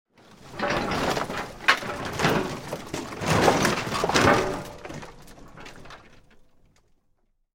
Звуки сломанной машины
Шумы неисправного двигателя автомобиля посторонние звуки и заклинивание